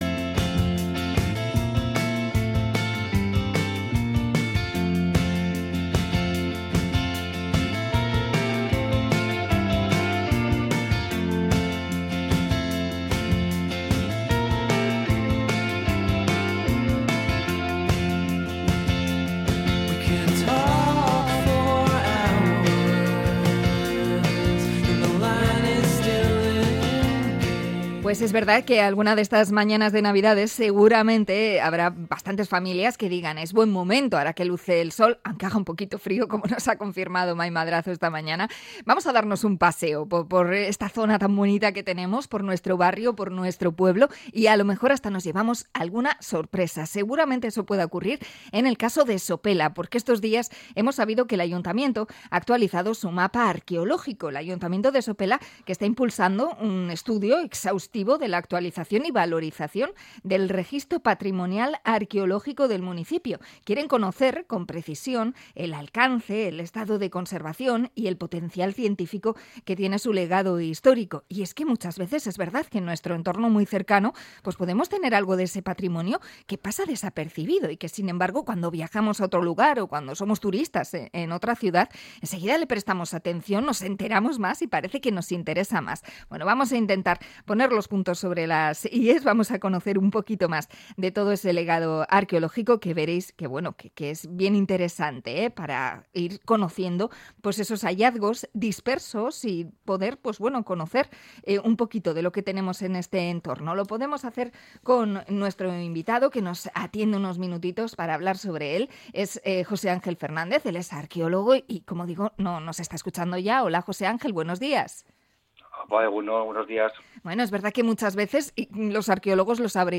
Entrevista con arqueólogo sobre el pasado arqueológico de Sopela